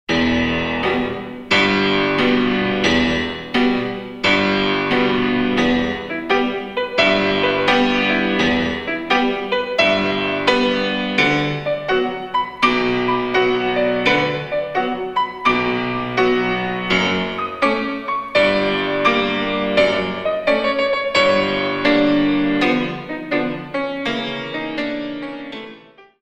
In 2
64 Counts